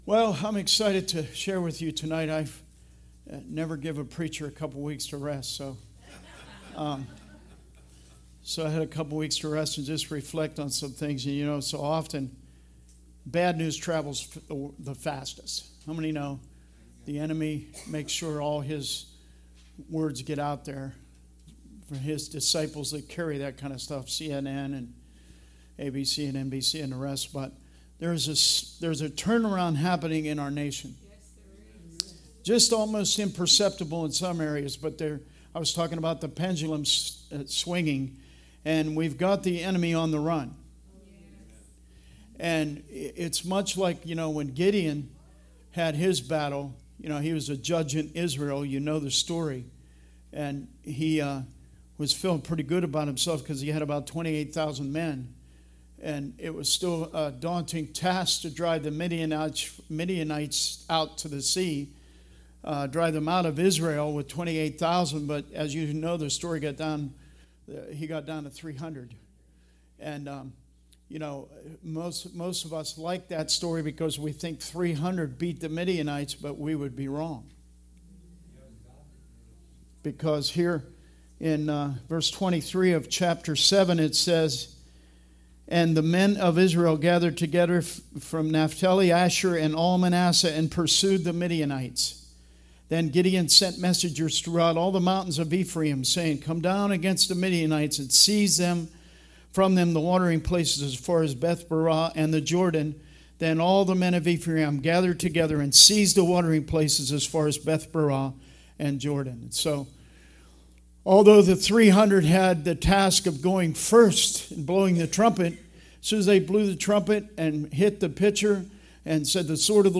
Service Type: Wednesday Teaching